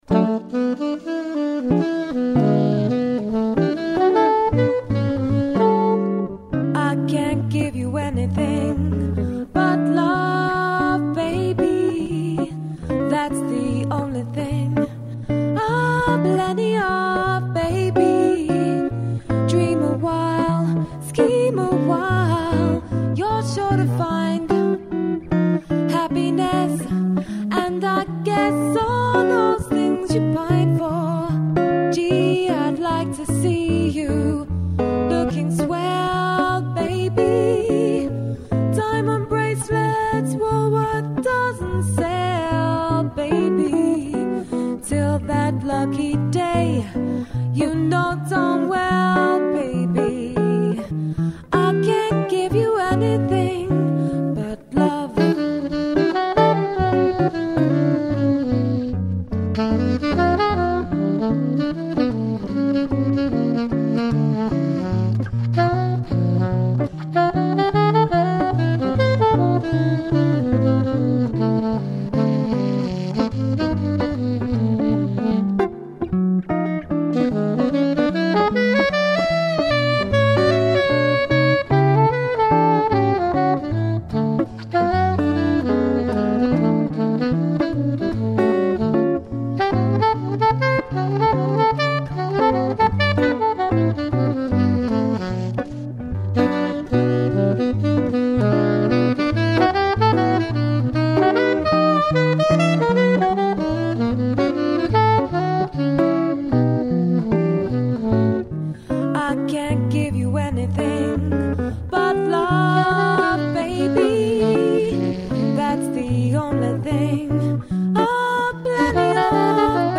Jazz Singer | Jazz Trio | Jazz Quartet | Jazz Band